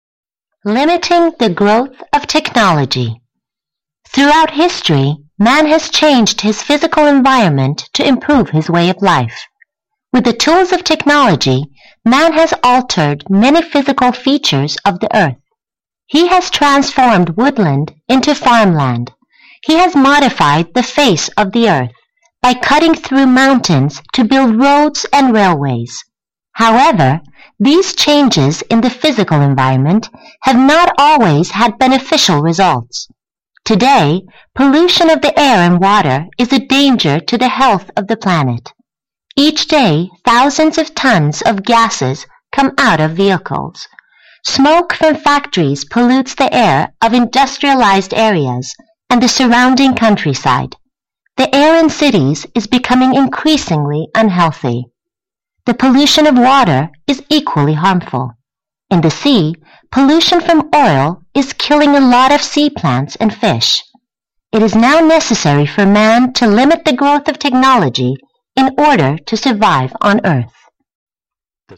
2014年英语专业四级听力真题 听写